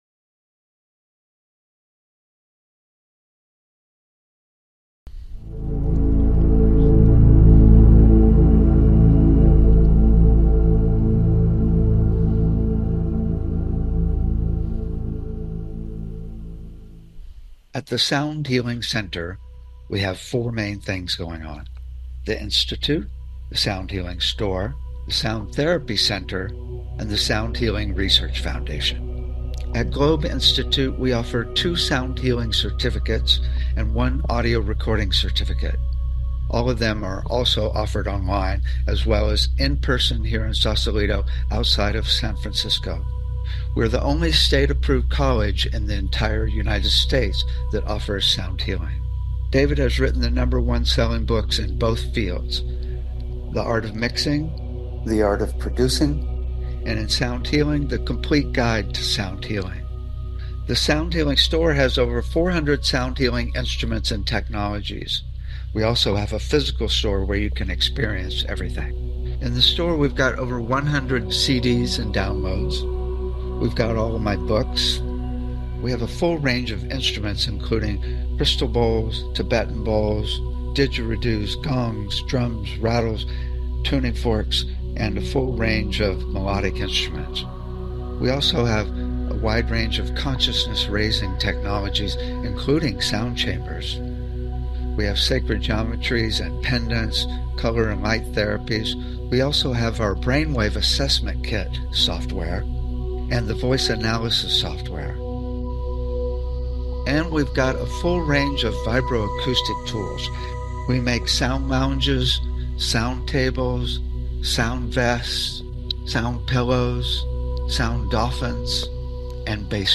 Talk Show Episode, Audio Podcast, Sound Healing and How to be at peace in the midst of challenges and conflicts.